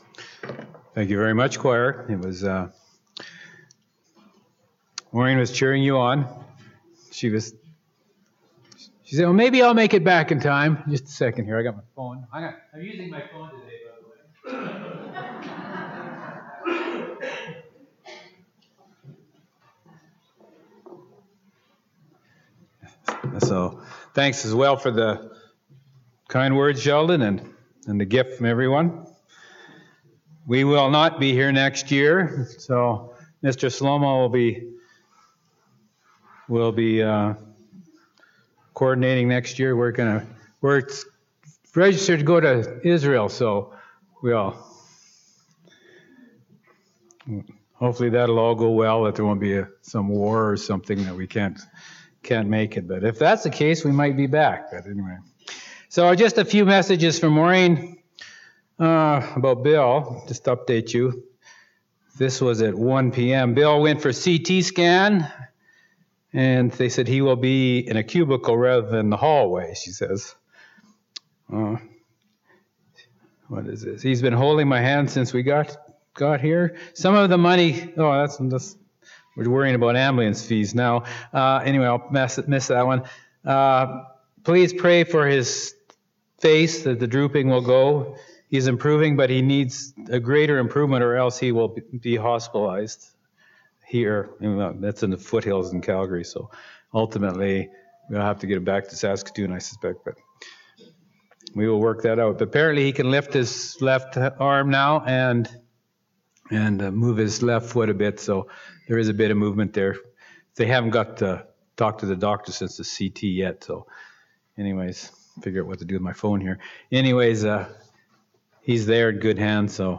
This sermon was given at the Cochrane, Alberta 2019 Feast site.